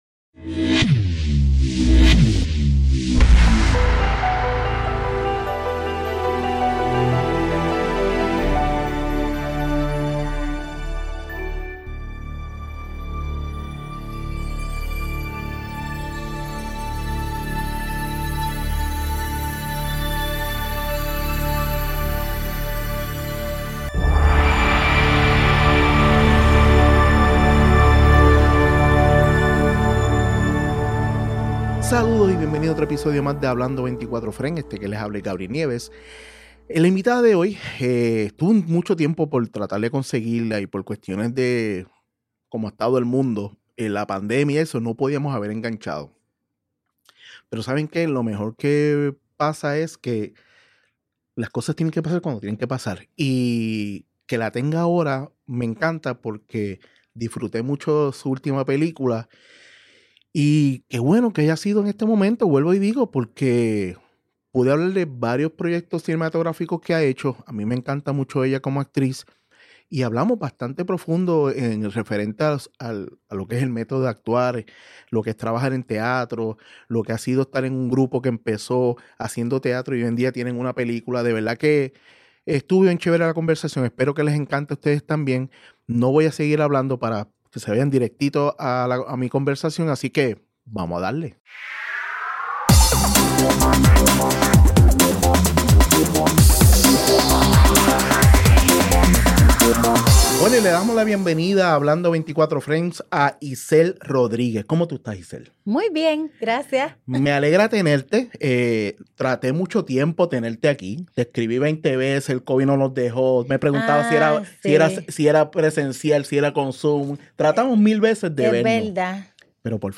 Grabado en GW-Cinco Studio para GW5 Network.